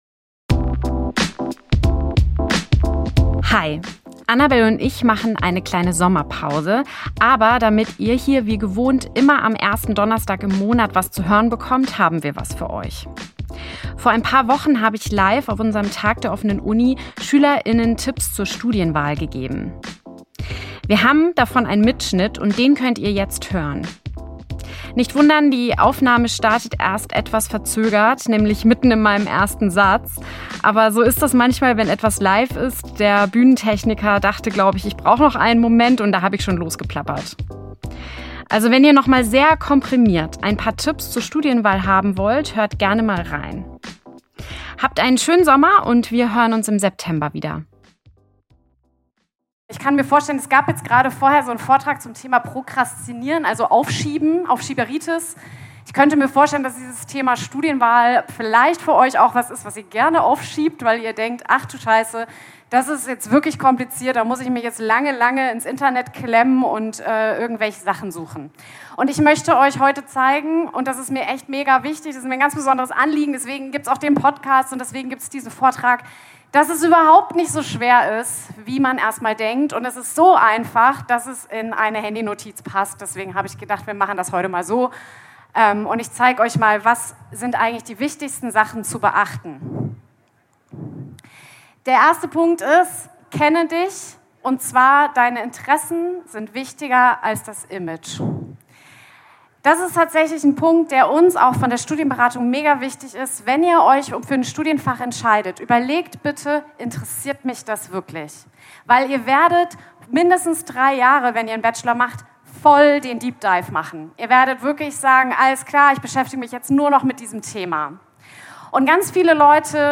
Summer Special - Lost bei deiner Studienwahl? (LIVE in Mainz) ~ Studieren!